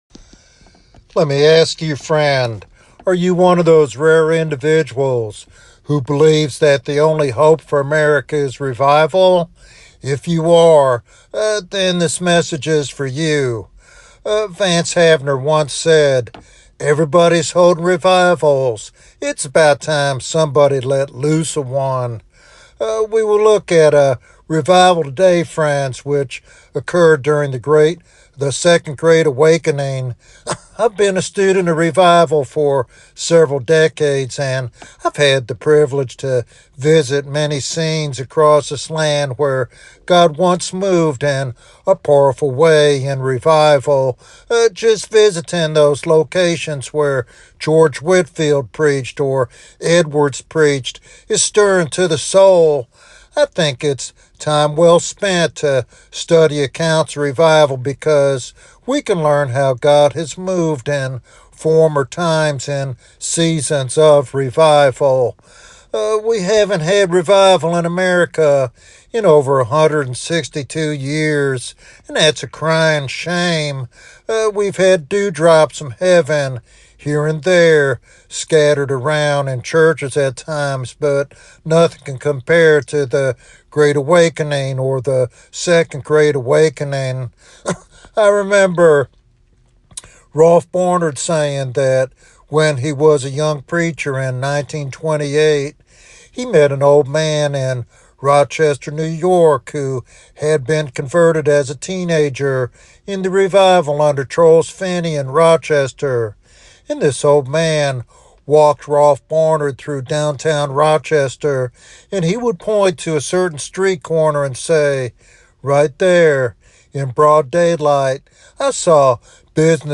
This sermon is a passionate call to repentance and prayer for a nation in desperate need of God's mercy.